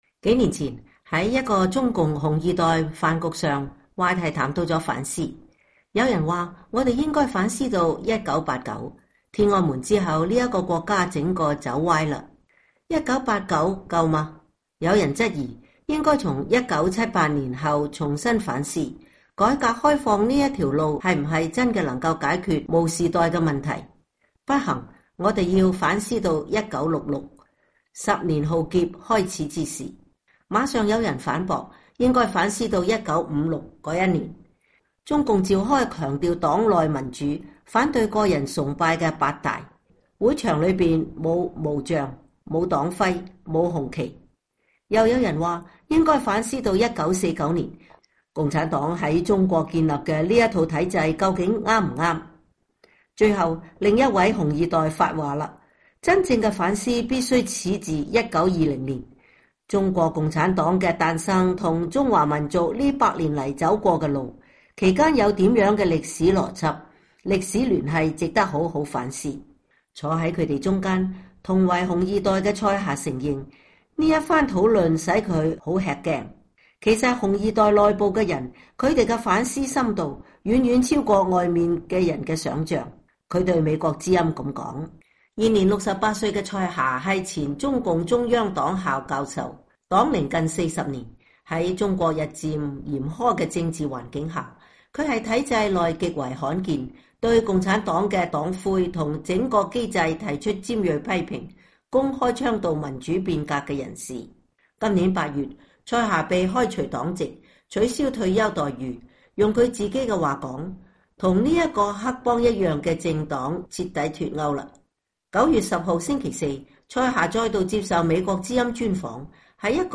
【獨家專訪】蔡霞：中國的政治出路 - 去習、非共、變革、和平